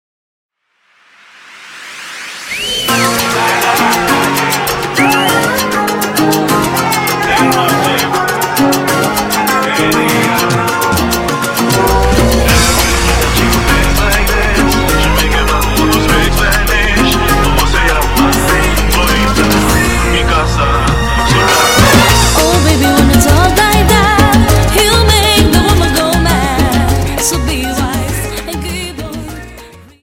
Dance: Salsa 50